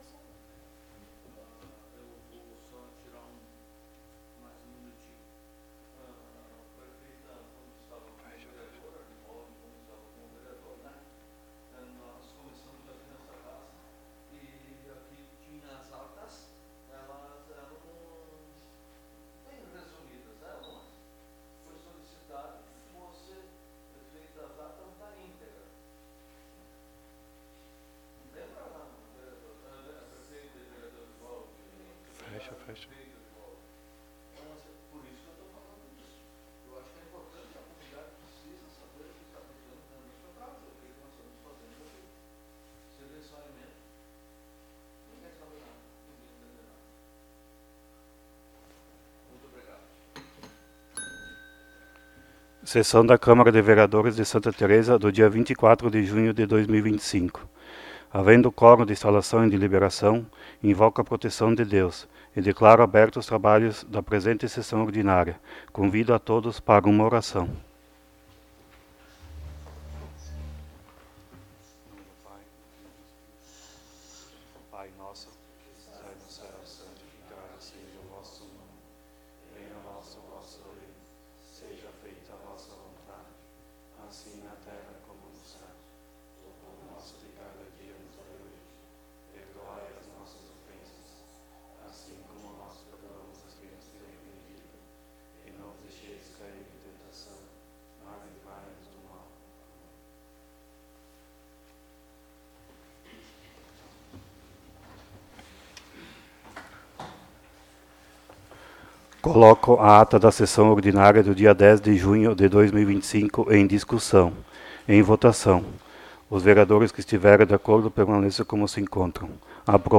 Áudio da Sessão